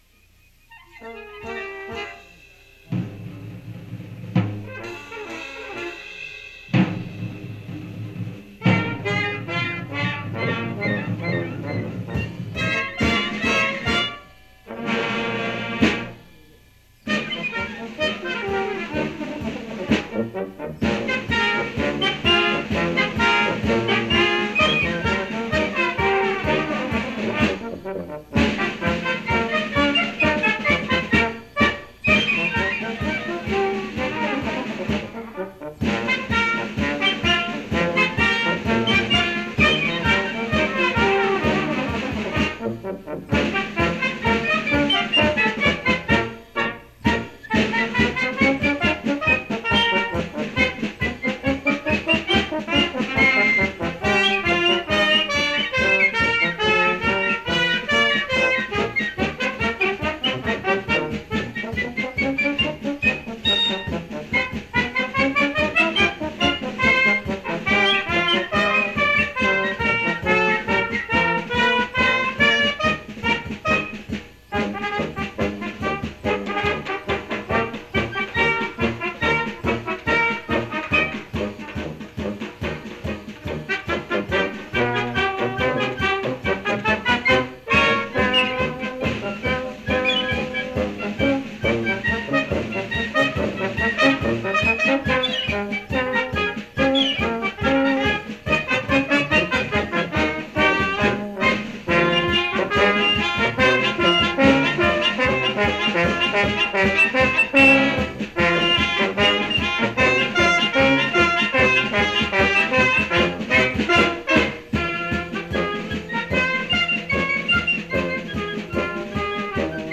Now you can listen to any or all of the playlist from that first WJU Convention in Key Biscayne, Florida in January,1973.
Dance of the Demons Novelty – Holtst (73 KB)